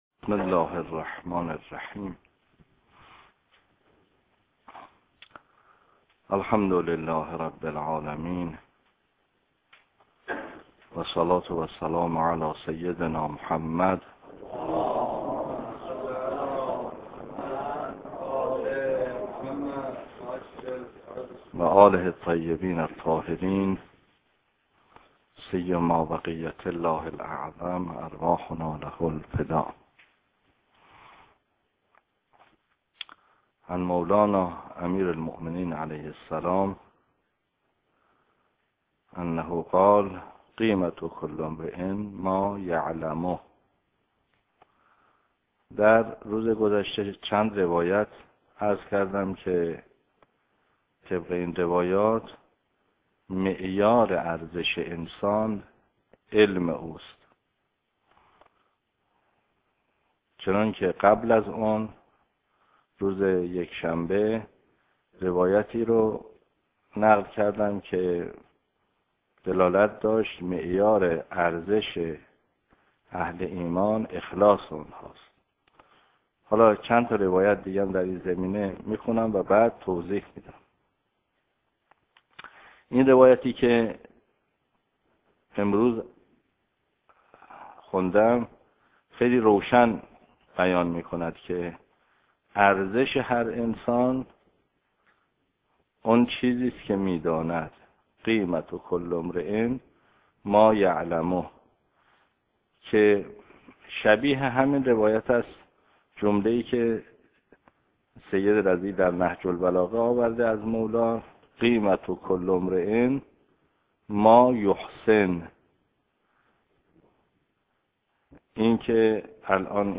درس خارج فقه استاد محمدی ری شهری